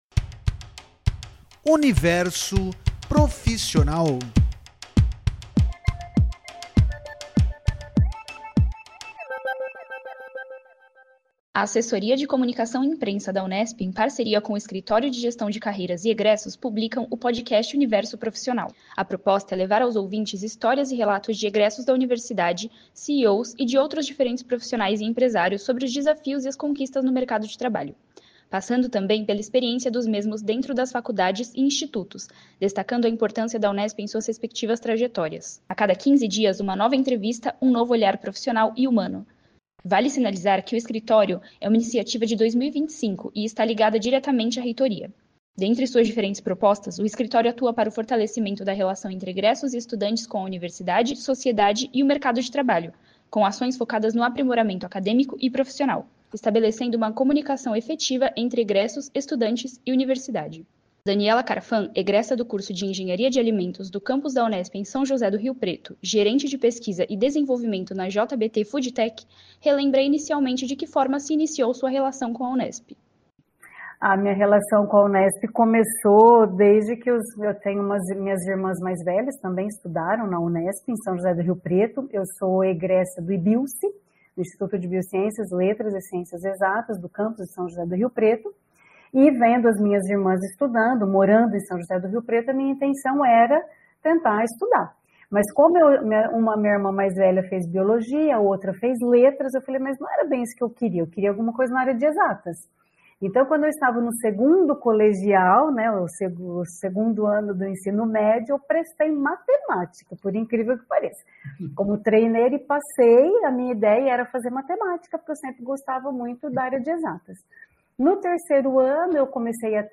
A proposta é divulgar histórias de estudantes formados pela Unesp e experiências de CEOs e empresários que atuam no mercado de trabalho. A cada quinze dias, uma nova entrevista e um novo olhar profissional e humano, em que destacamos a importância da Unesp para as trajetórias destas pessoas.